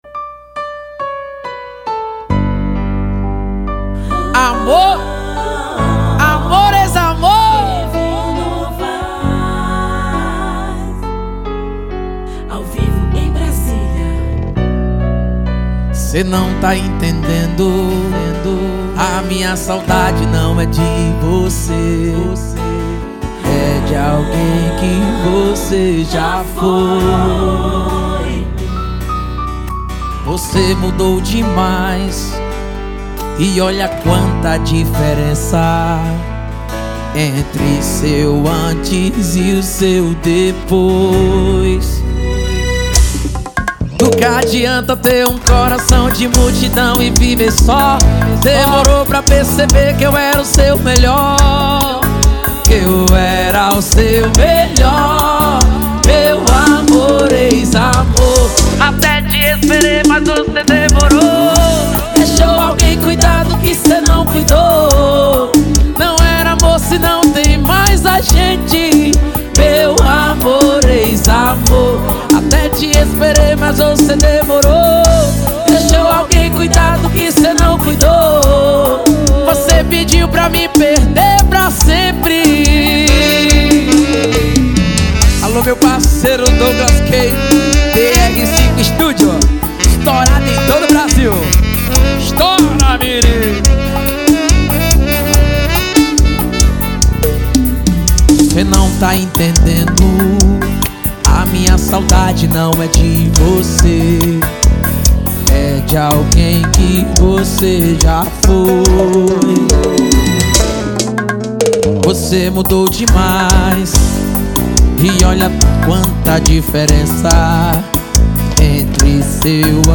uma música